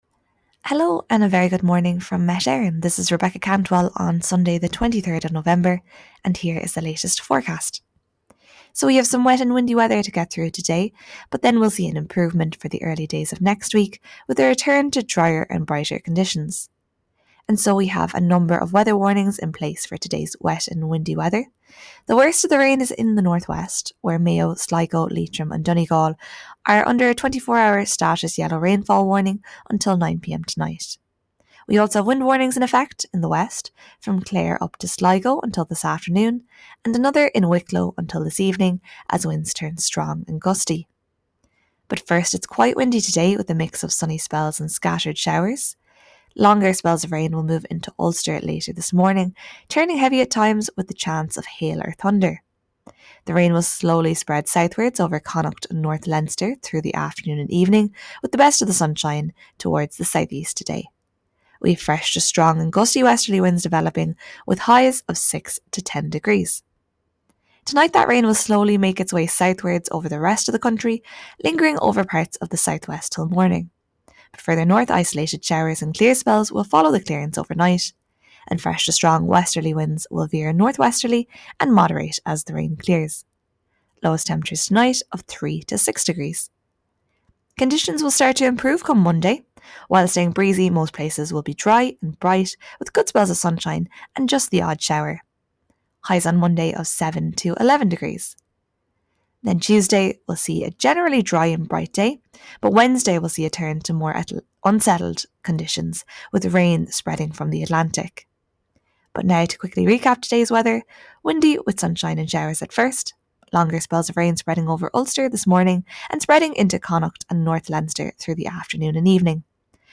Weather Forecast from Met Éireann